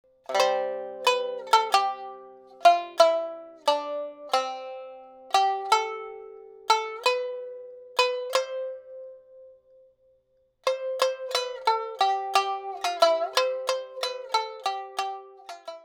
• niagari tuning
• techniques: sukui (ス upstroke)
majestic and beautiful, and it is festive and glorious
shamisen